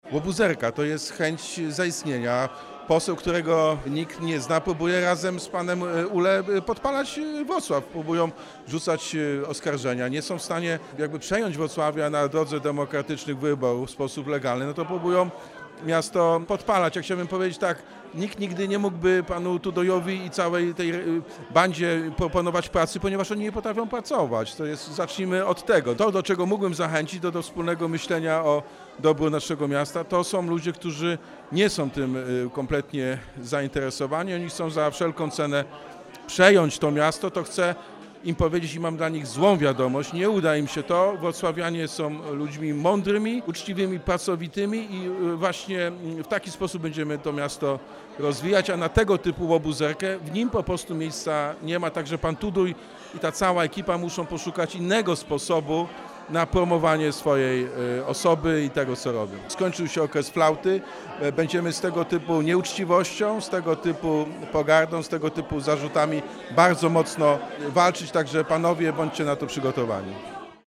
Prezydent Jacek Sutryk również dziś skomentował sprawę.